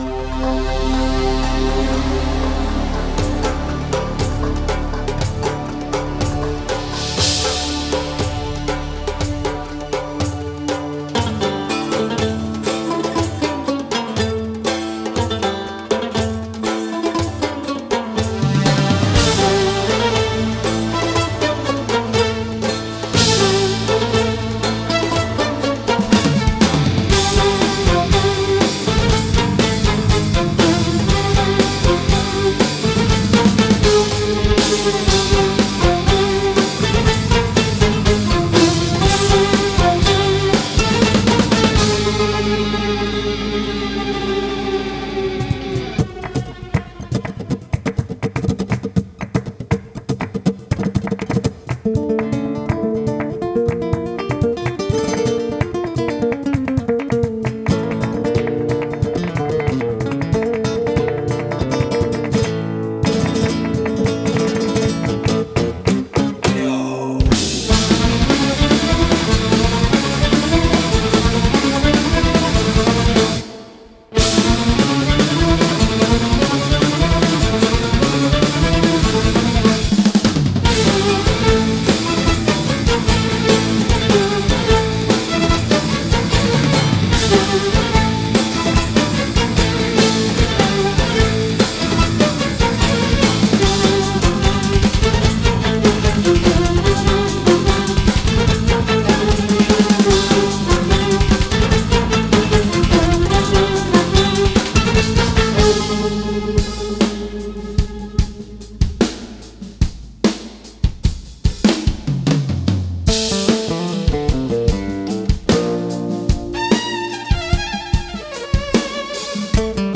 Genre: Guitar Virtuoso, Speed Metal, Flamenco